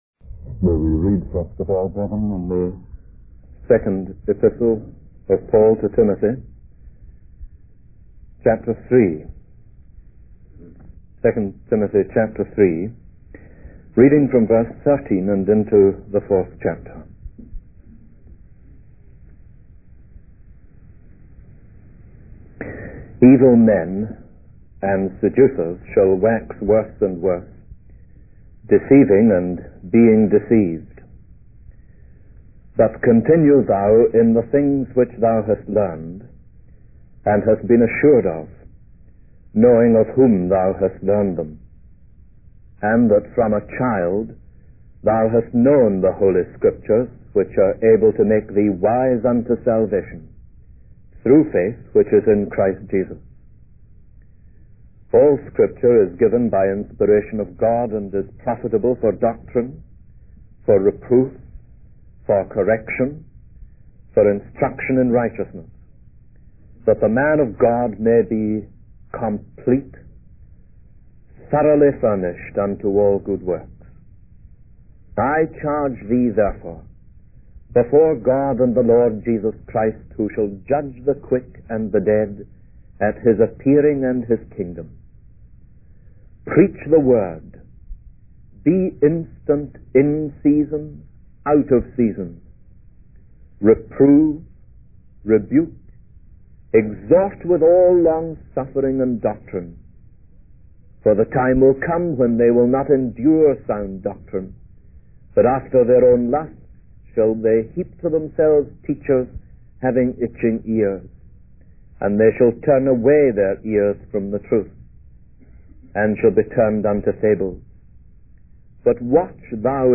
The video is a sermon on the task to which believers are called, focusing on the words of the apostle Paul in 1 Timothy 4:1-2. The speaker emphasizes the importance of the Word of God and the role of the Holy Spirit in its proclamation.